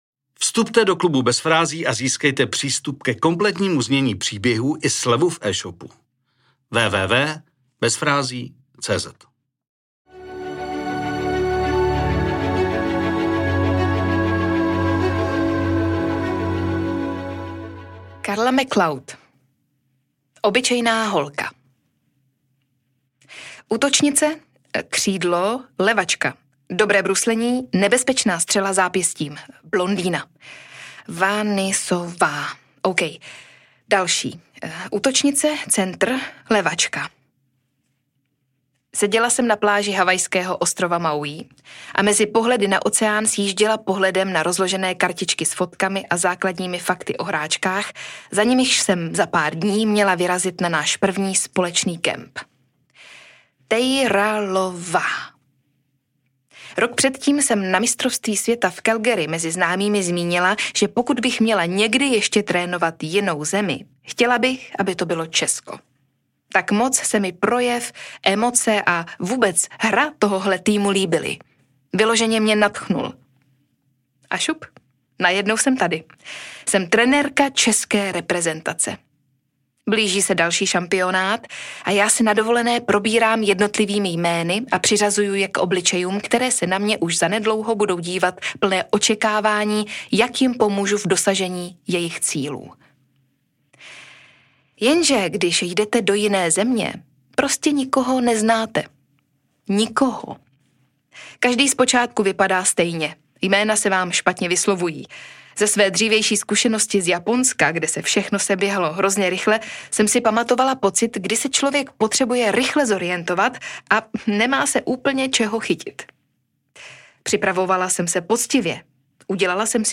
V audioverzi si ho můžete poslechnout namluvený a zahraný hlasem úžasné Veroniky Khek Kubařové.